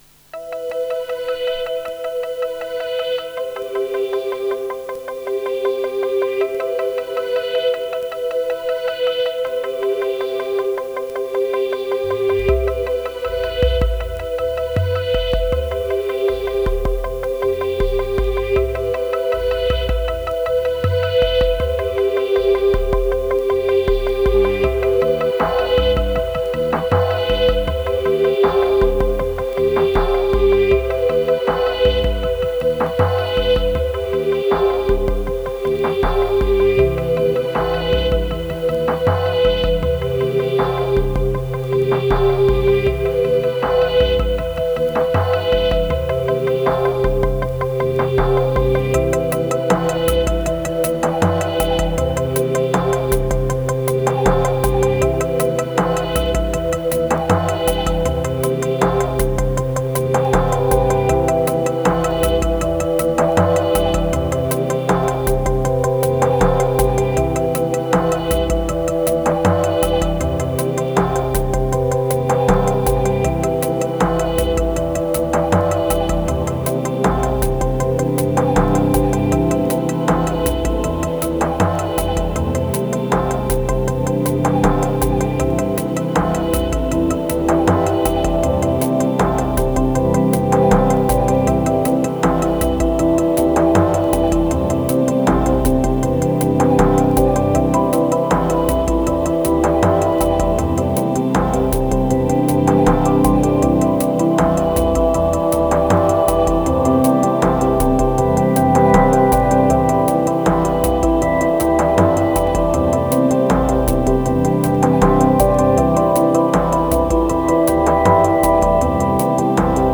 522📈 - 91%🤔 - 79BPM🔊 - 2025-08-14📅 - 523🌟
Low energy ambient beats.